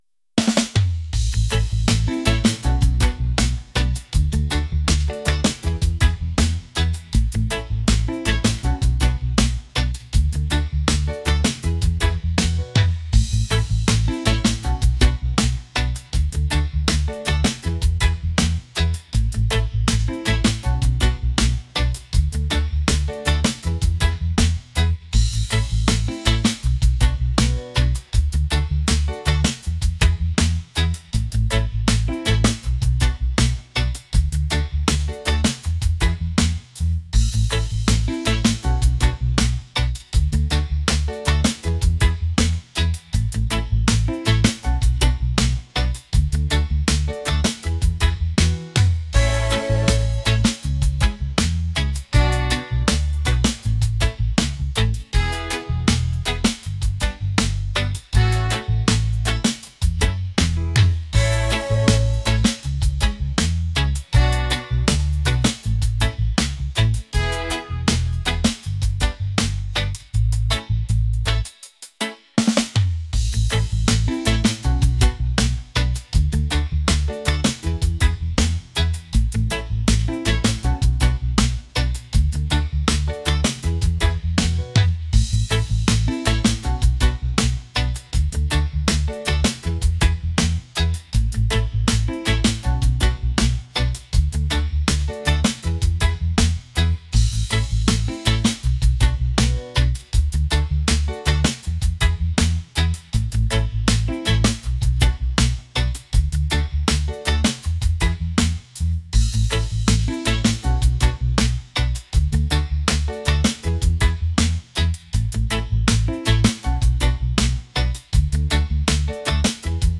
reggae | upbeat | positive